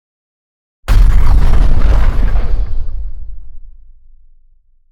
Explosion 3